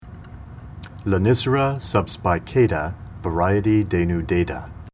Pronunciation Cal Photos images Google images